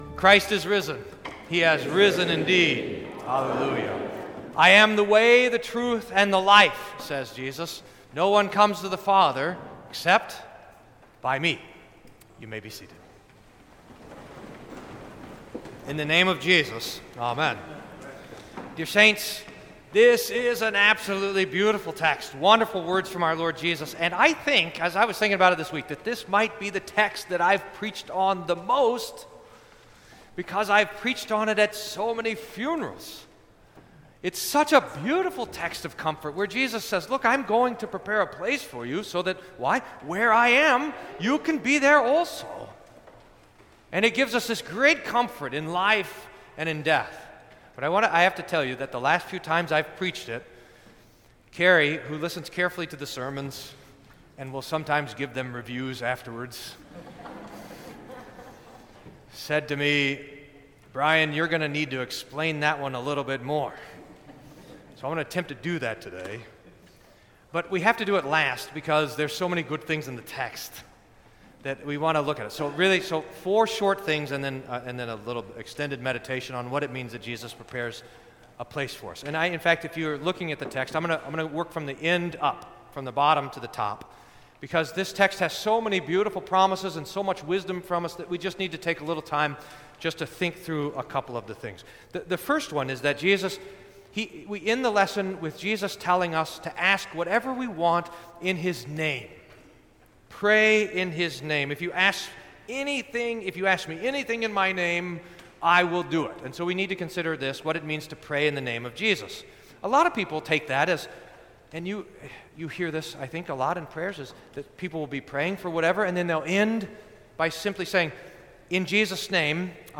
Sermon for Fifth Sunday of Easter